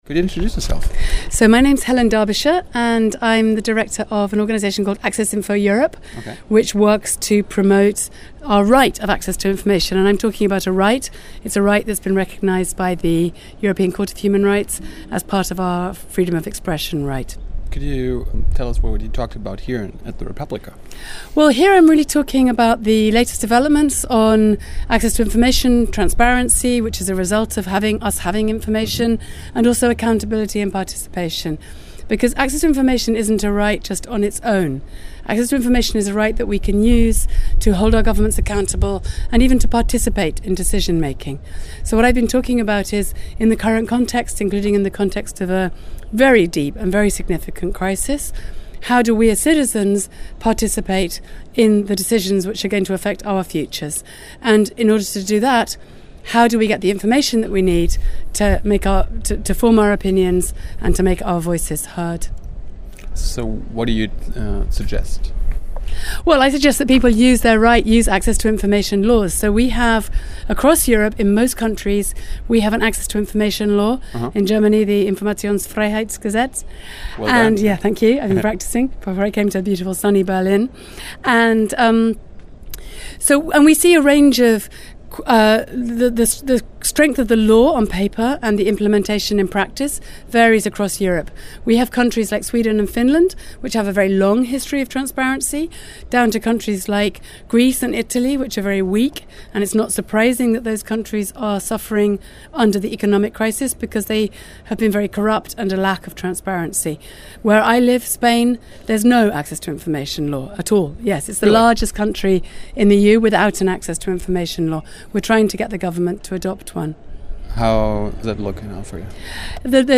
Interview in englisch am Rande der re:publica 2012 Wo : Station Berlin